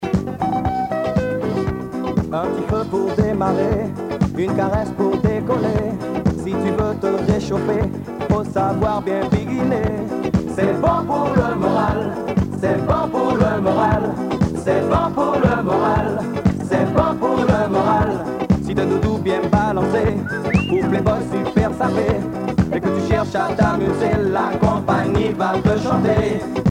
danse : biguine
Pièce musicale éditée